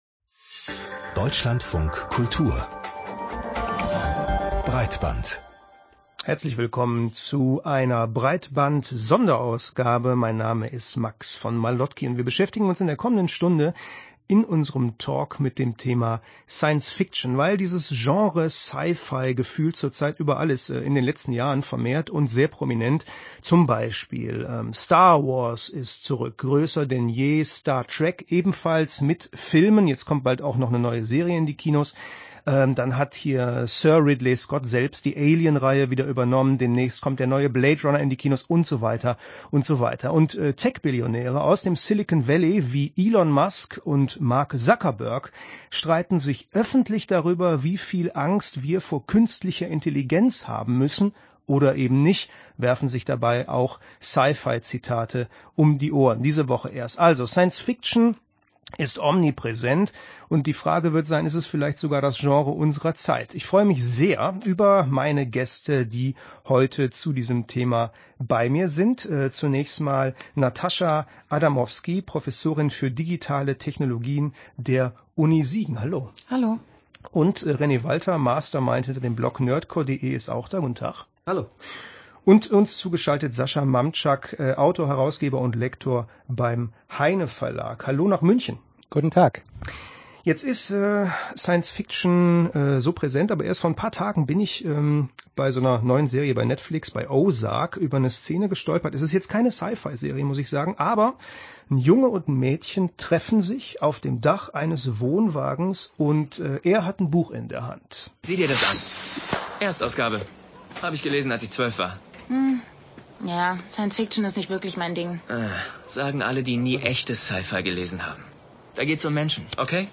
2017-dlf-zukunft-breitband-sci-fi-talk.mp3